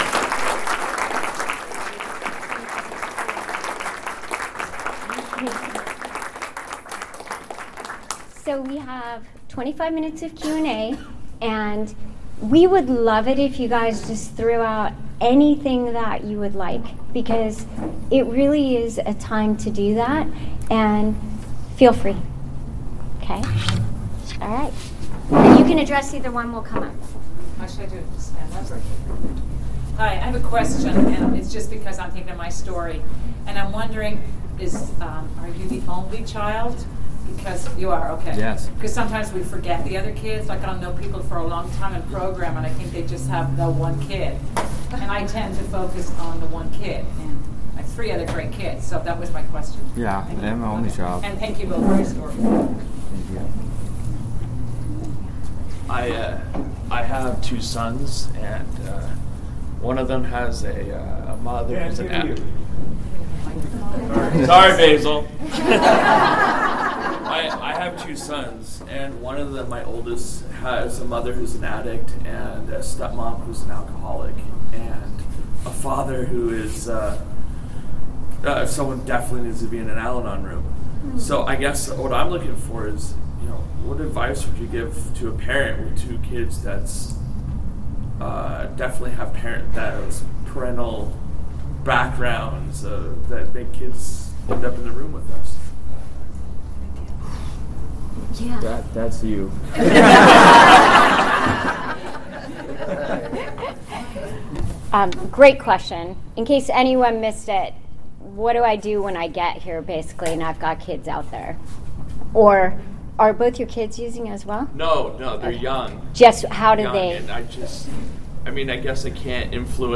35th Annual Ventura Serenity By The Sea
Q and A